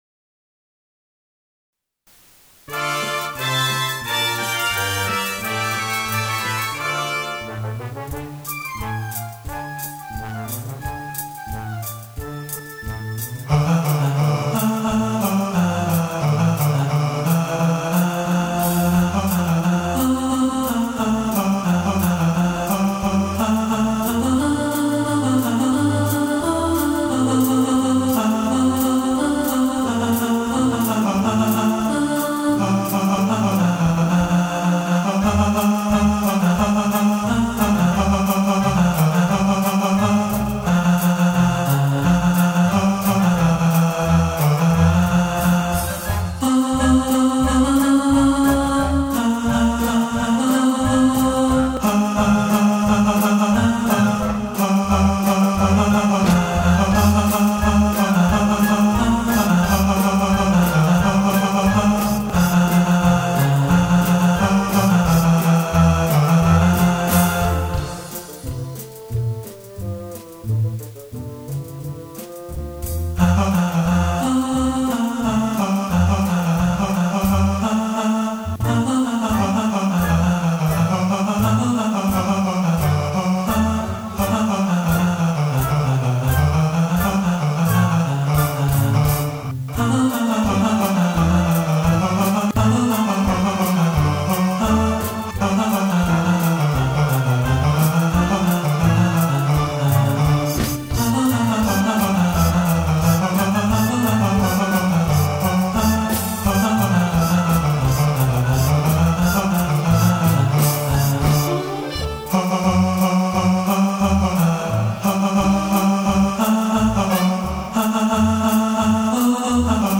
Rhythm Of Life – Tenor | Ipswich Hospital Community Choir
Rhythm-Of-Life-Tenor.mp3